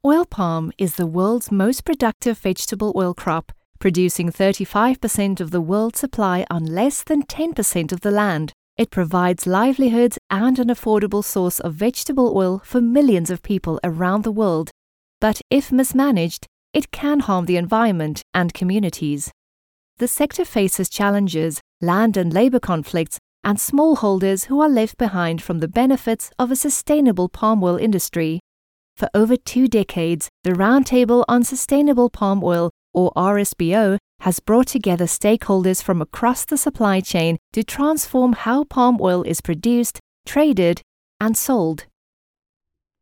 English (South African)
Explainer Videos
Behringer C1 Condenser microphone
Sound-proofed room
HighMezzo-Soprano